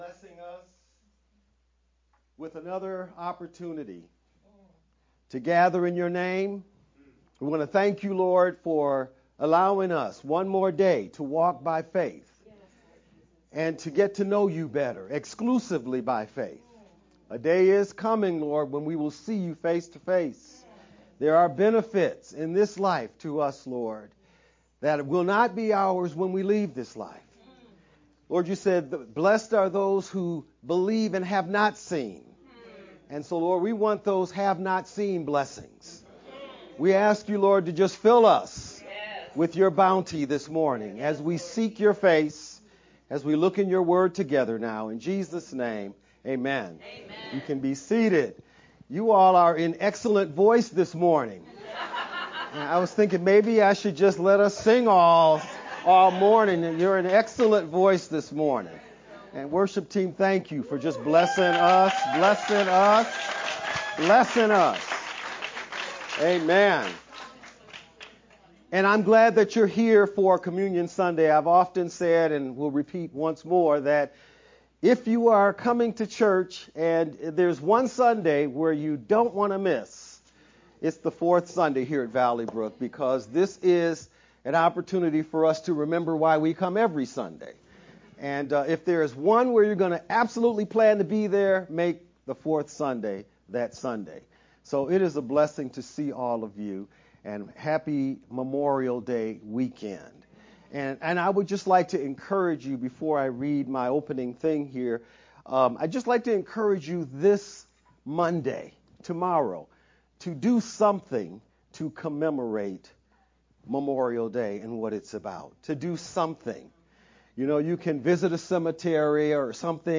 5-25-VBCC-Sermon-only-edited_Converted-CD.mp3